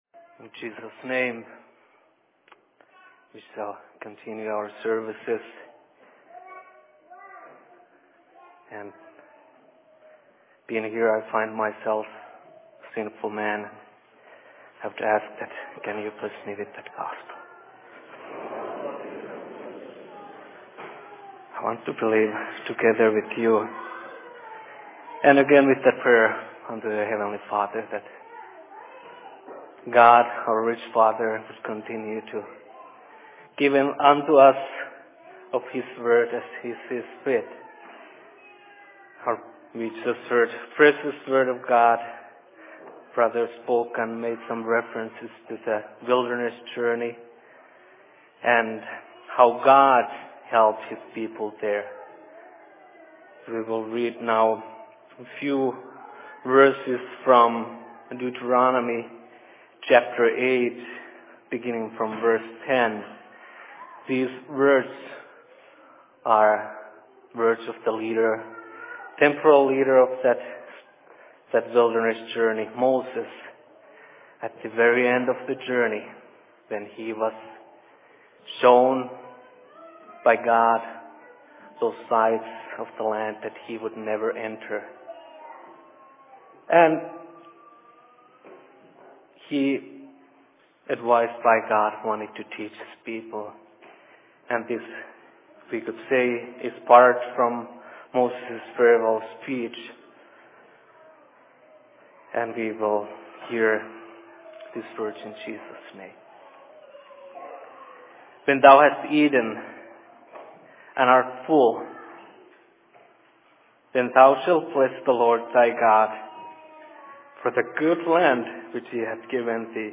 Sermon in Minneapolis 25.11.2011
Location: LLC Minneapolis